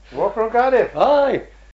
the-phonology-of-rhondda-valleys-english.pdf
5_8_2.4._from_Cardiff_aye.mp3